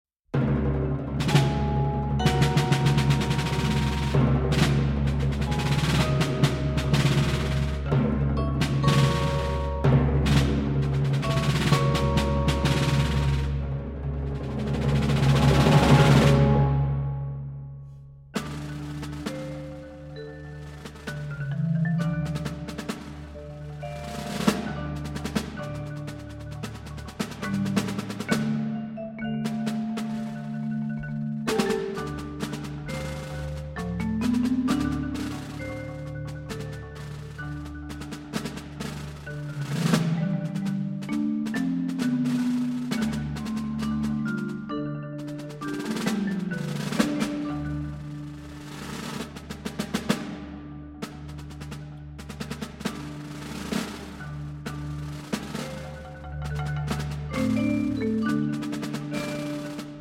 Marimba
Vibraphone
Timpani